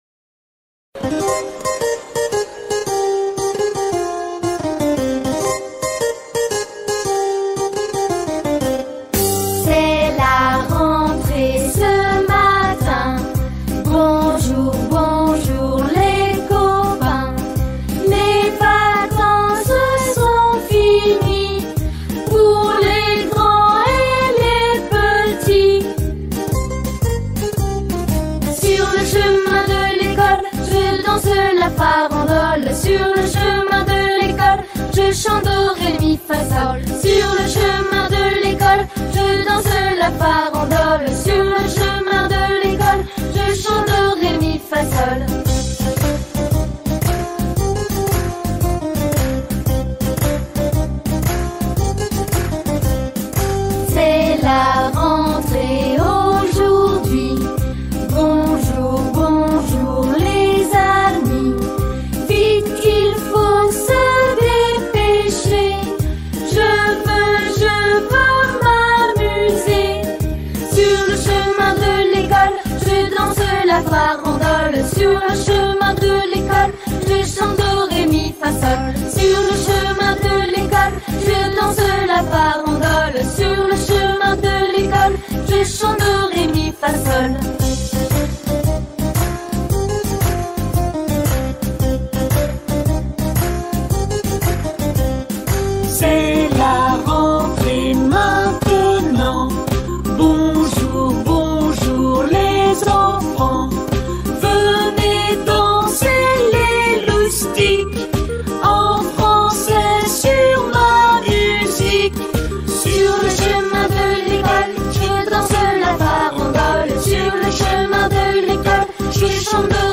Comptine de la rentrée
Cest-la-rentree-Chanson.mp3